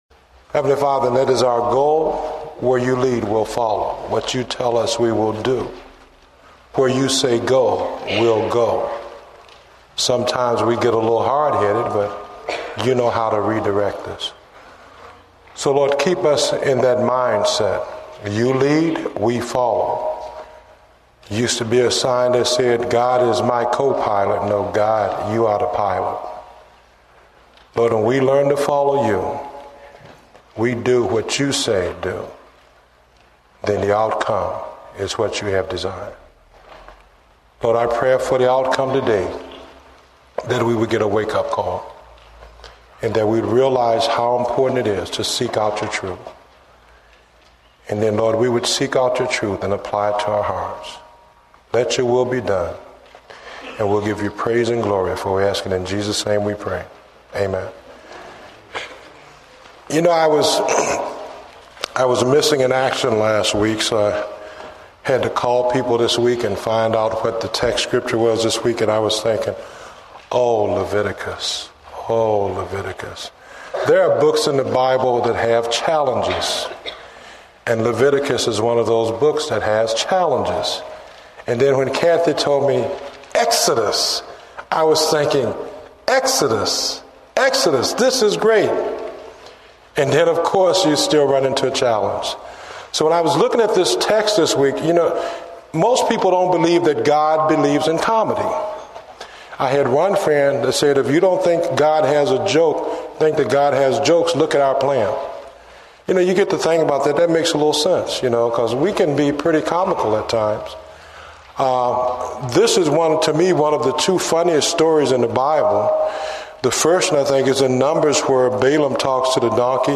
Date: February 8, 2009 (Morning Service)